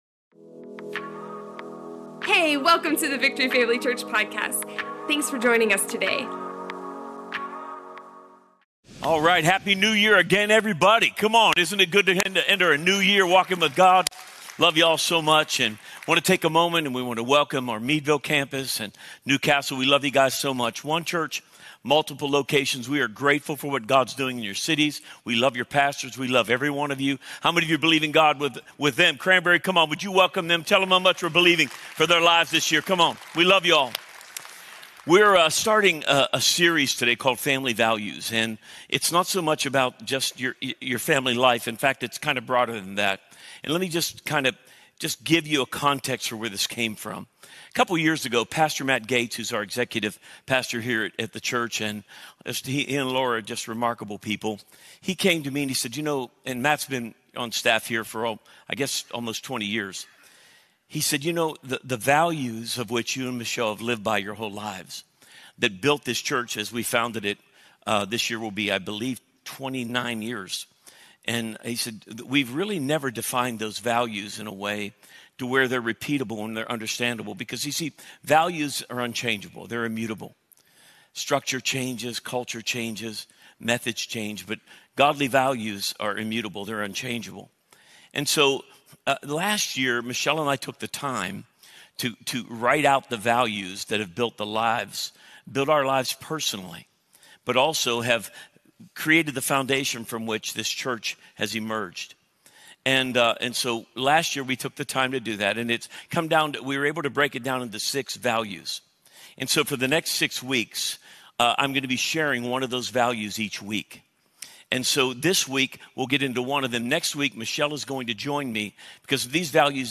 Access sermon video, audio, and notes from Victory Family Church online today!